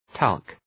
Προφορά
{tælk}
talc.mp3